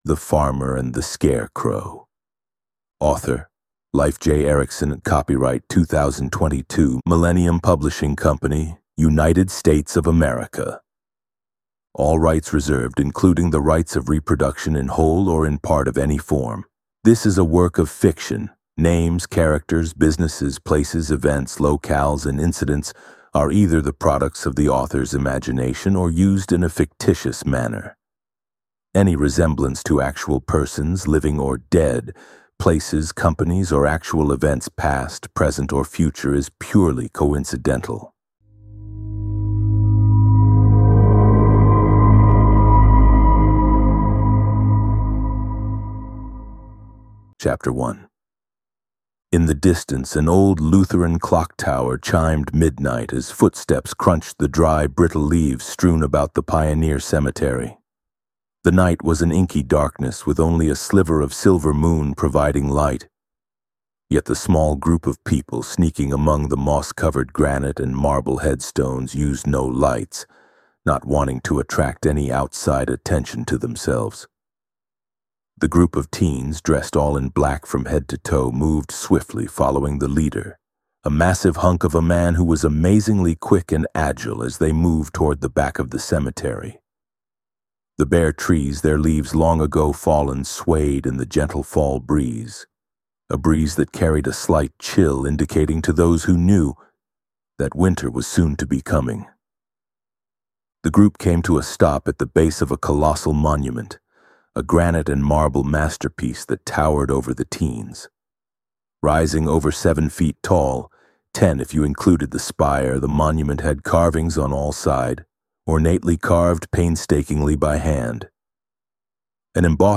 farmer-and-scarecrow-audiobook